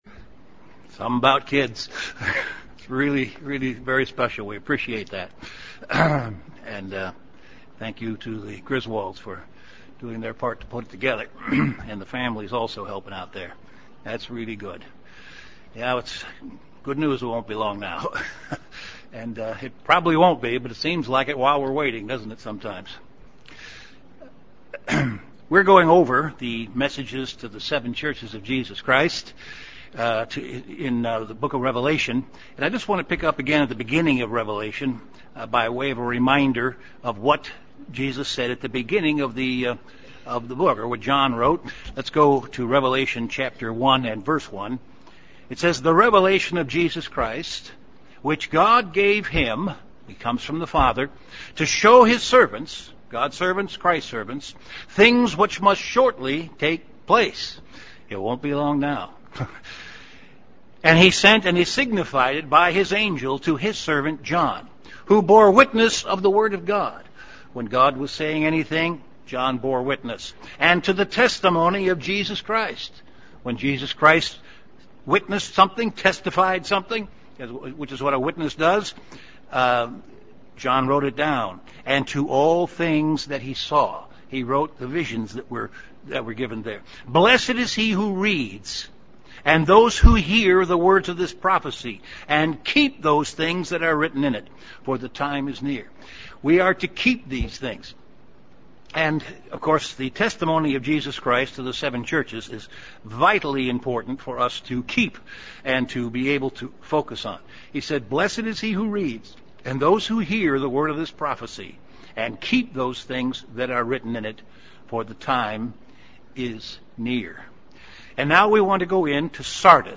Given in Chicago, IL Beloit, WI
UCG Sermon